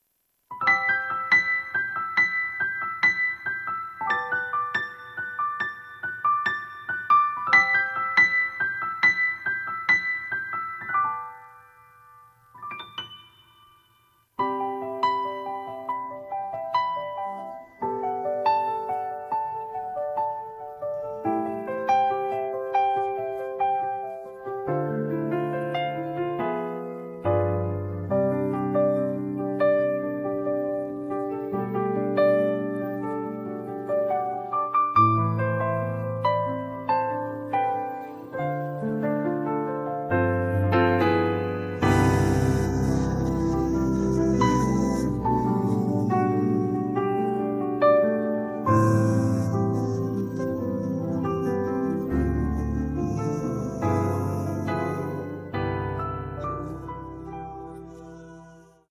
음정 -1키 3:19
장르 가요 구분 Voice MR